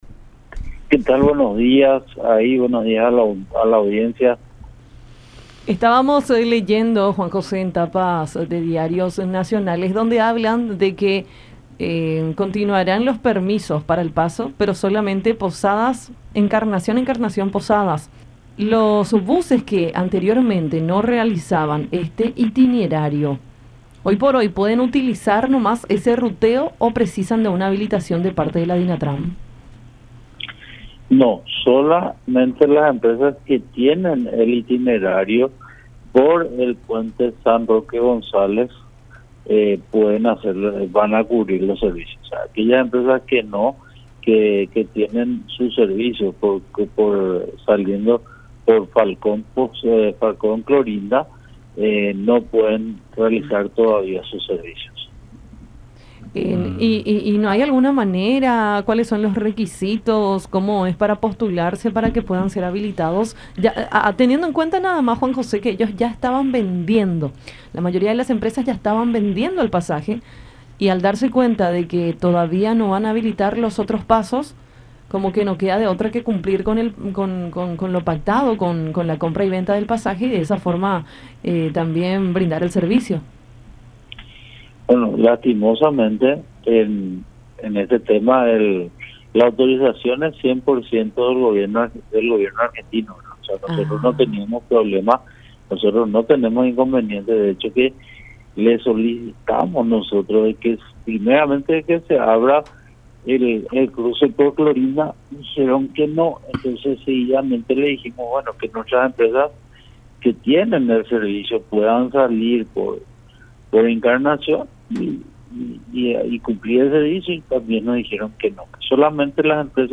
“Solo las empresas que tienen el itinerario por el puente San Roque González pueden hacer este servicio”, aclaró el titular de la Dirección Nacional de Transporte (DINATRAN), Juan José Vidal, en diálogo con Enfoque 800 por La Unión, en referencia a la habilitación del paso declarada por el Gobierno de Argentina este lunes.